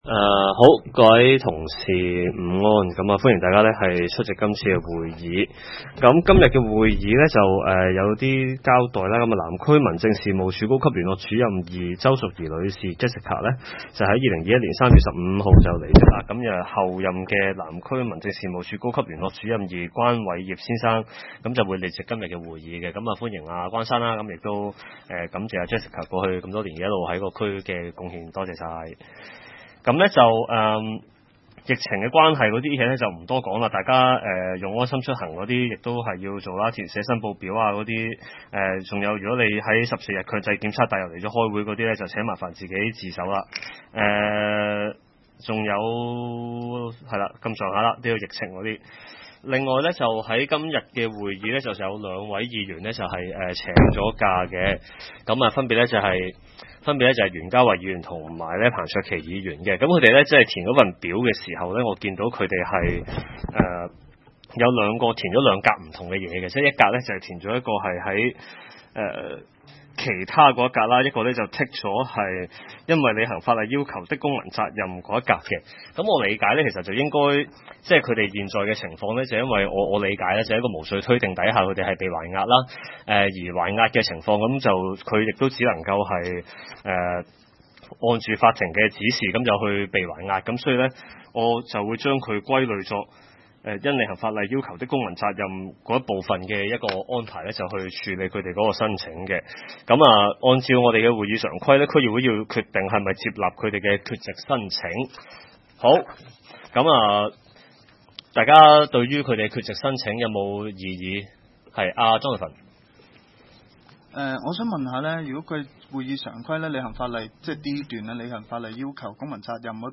南区区议会大会的录音记录
南区区议会会议室